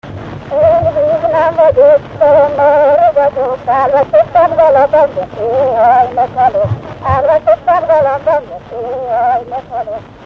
Erdély - Udvarhely vm. - Szentegyházasfalu